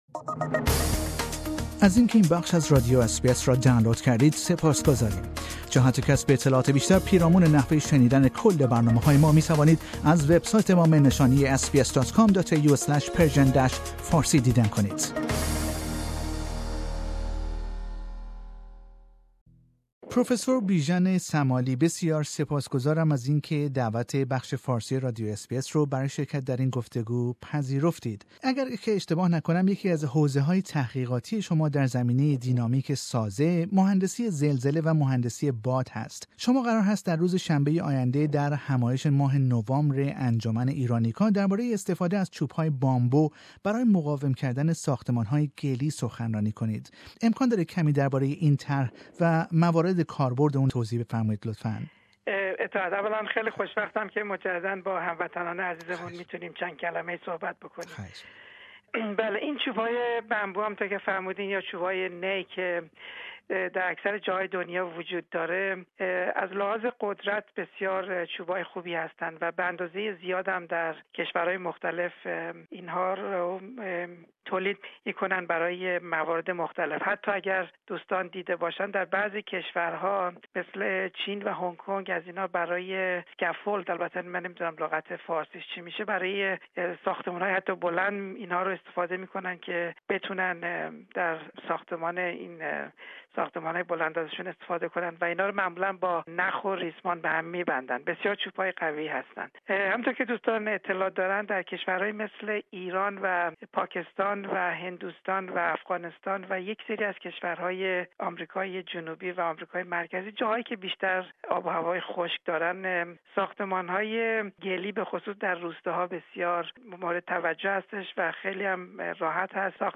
در گفتگو با بخش فارسی رادیو اس بی اس